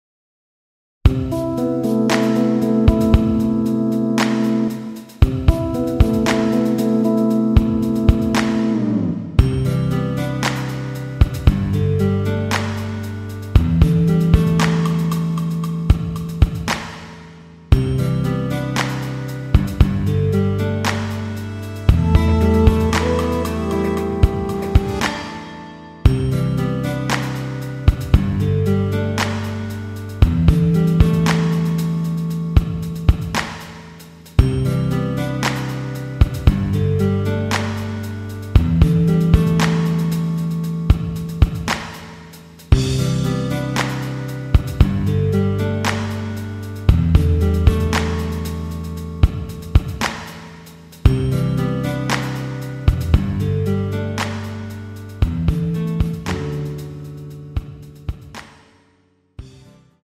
Em
앞부분30초, 뒷부분30초씩 편집해서 올려 드리고 있습니다.